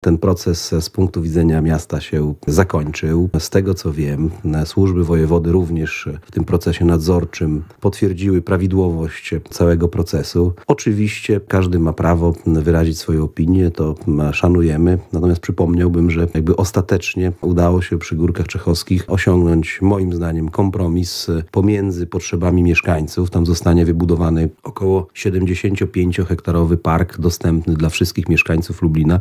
– Emocje z tym związane powoli się wygaszają – mówił gość porannej rozmowy Radia Lublin, zastępca prezydenta miasta Lublin ds. inwestycji i rozwoju Tomasz Fulara.